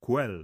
ue /ʊ̯ɛ/